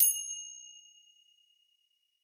finger_cymbals_side05
bell chime cymbal ding finger-cymbals orchestral percussion sound effect free sound royalty free Sound Effects